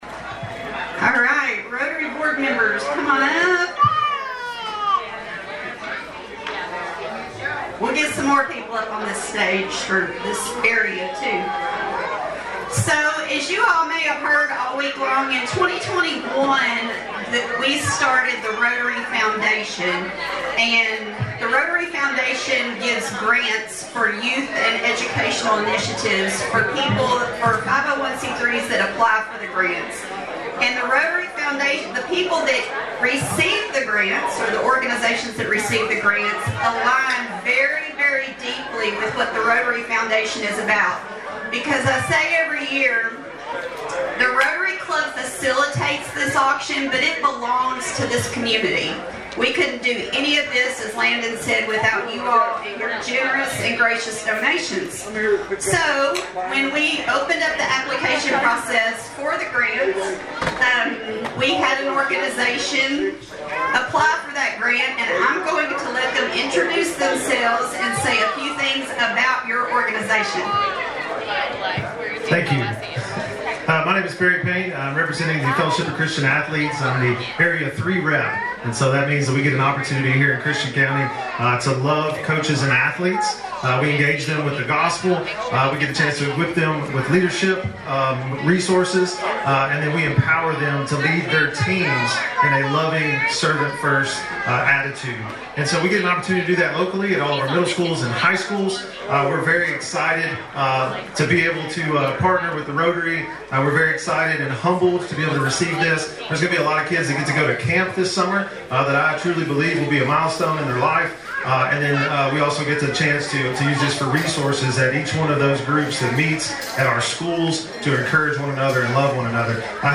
Always looking to raise “one more dollar than Cadiz,” Wednesday night’s efforts at the 75th Annual Hopkinsville Rotary Auction inside the War Memorial Building helped the club move well past $200,000 in fundraising.